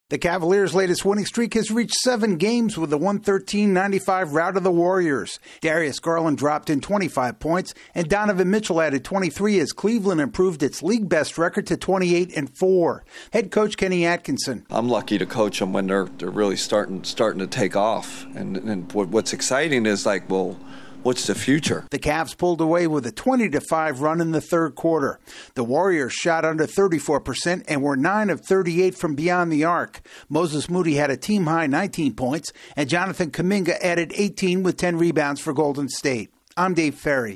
The Cavaliers continue to pile up wins. AP correspondent